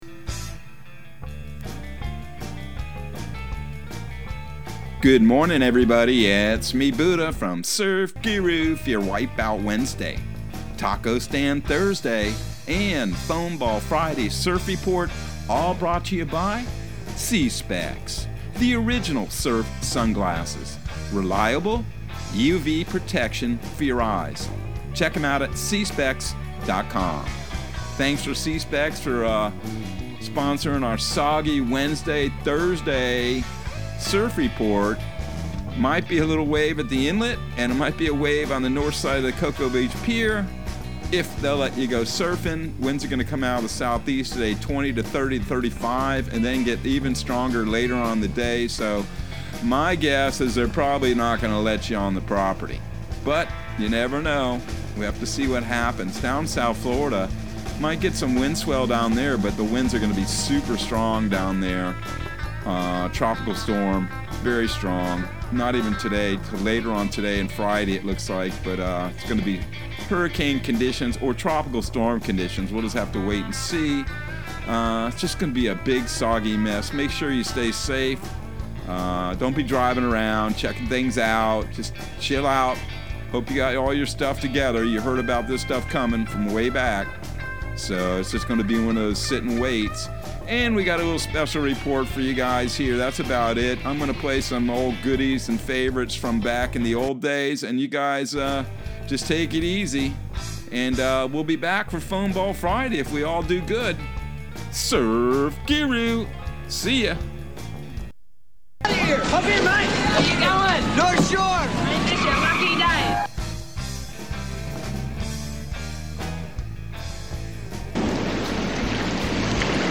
Surf Guru Surf Report and Forecast 09/28/2022 Audio surf report and surf forecast on September 28 for Central Florida and the Southeast.